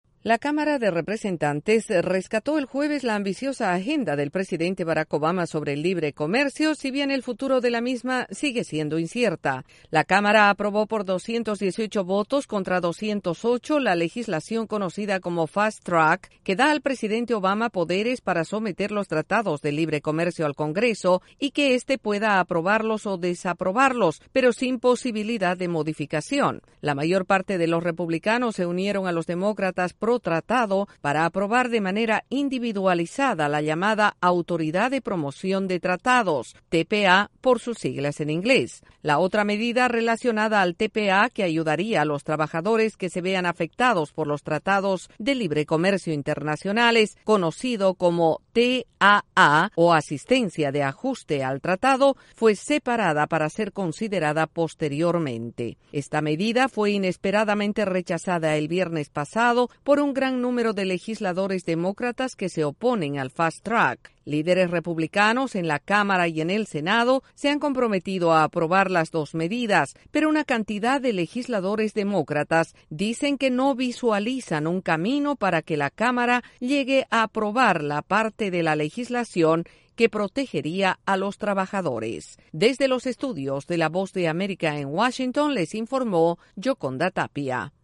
Legisladores en la Cámara de Representantes aprueban, en primera instancia, la agenda comercial del presidente Barack Obama. Desde la Voz de América en Washington informa